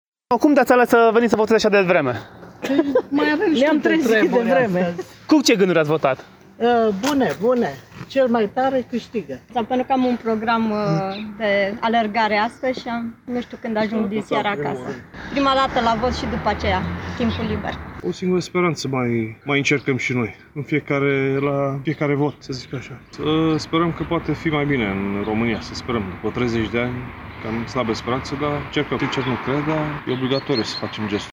voxuri-BV.mp3